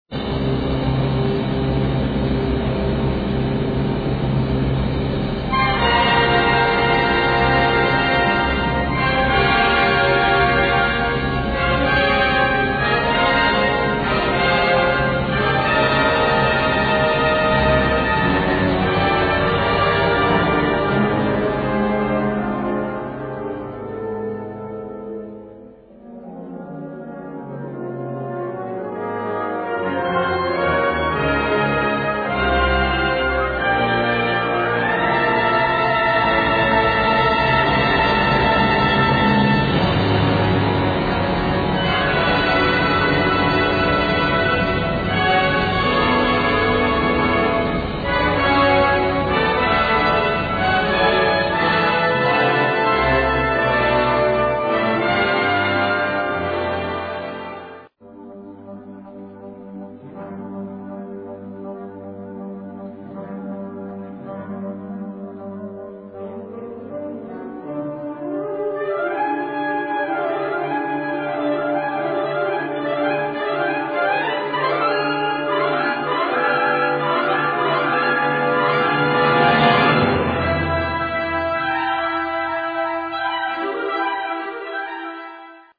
harmonieorkest